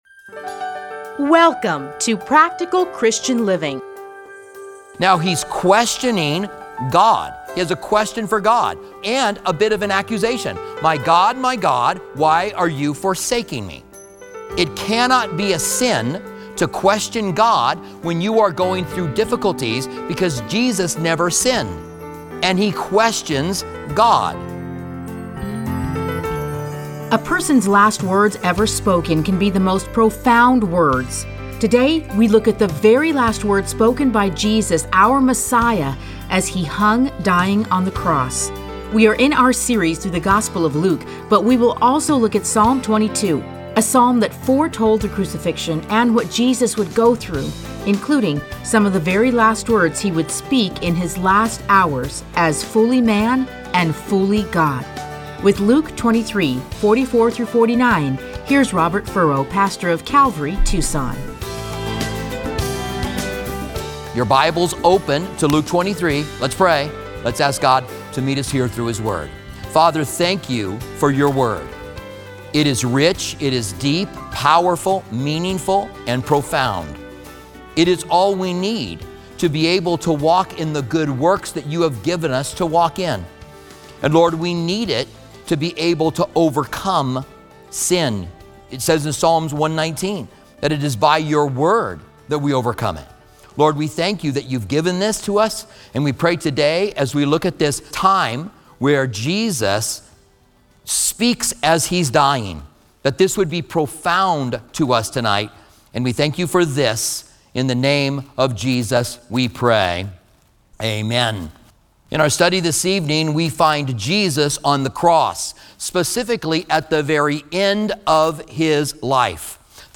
Listen to a teaching from Luke 23:44-49.